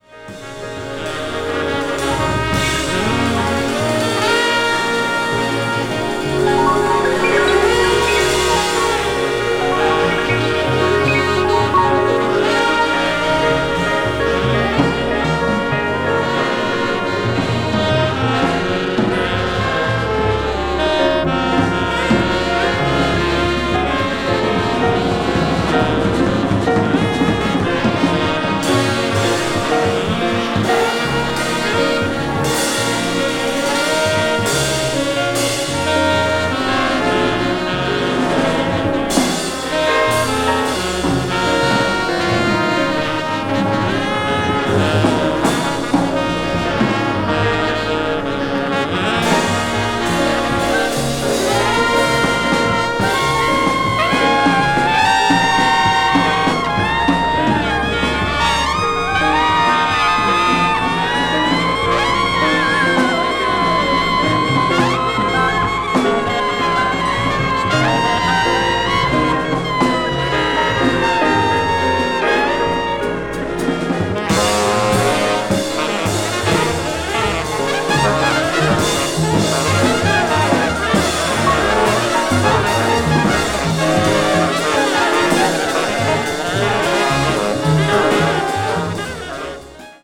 avant-jazz   free improvisation   free jazz